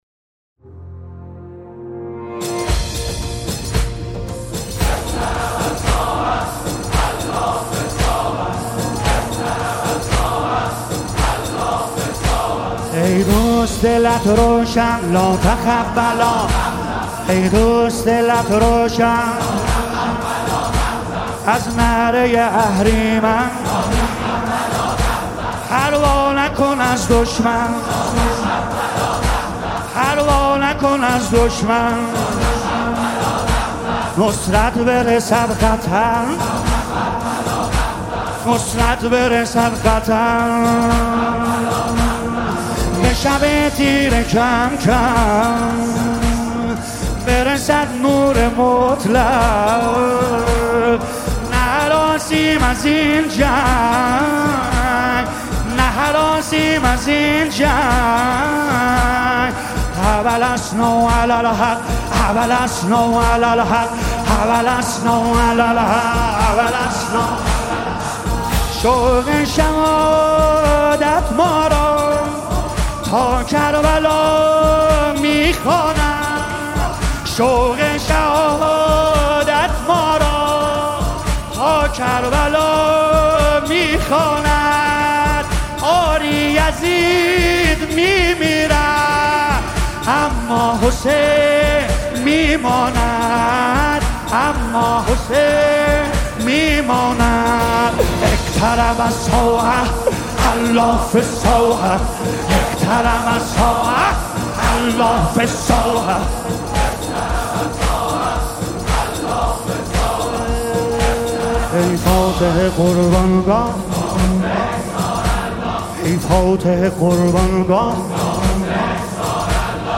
نماهنگ ماه محرم
مداحی ماه محرم